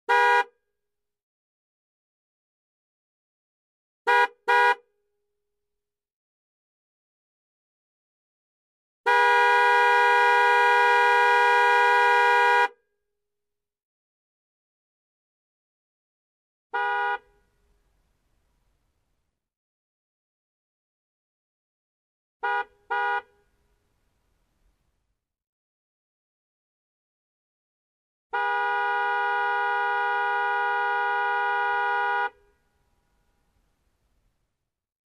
Звуки сигнала машины
На этой странице собраны разнообразные звуки автомобильных сигналов: от стандартных гудков до экстренных клаксонов.